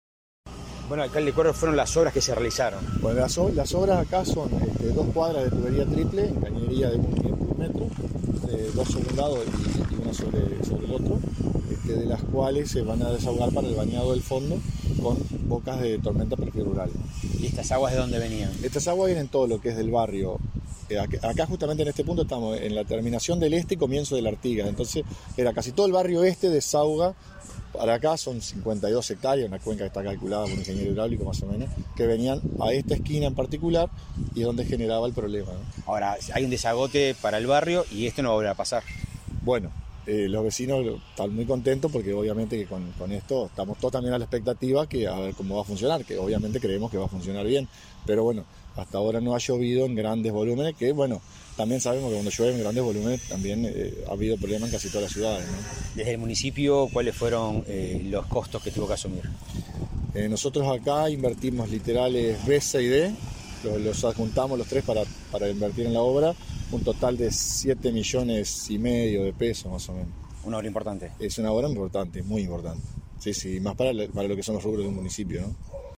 Entrevista al alcalde de Nueva Palmira, Agustín Callero